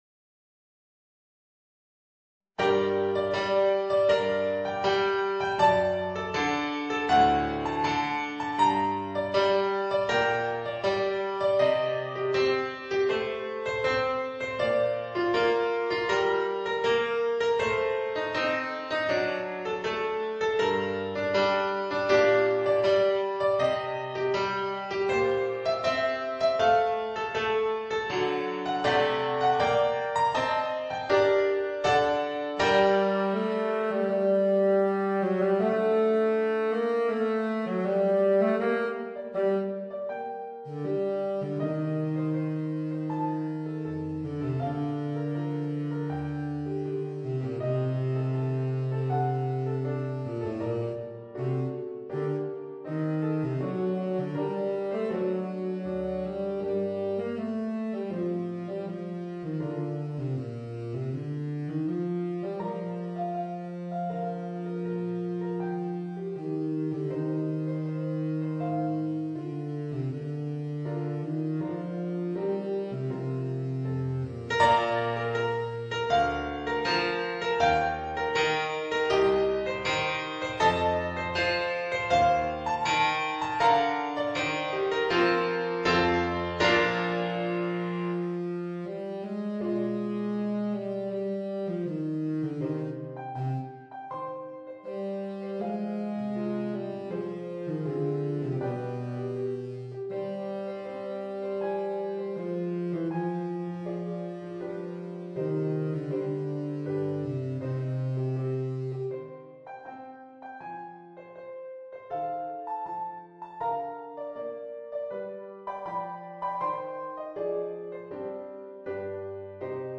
Voicing: Baritone Saxophone and Organ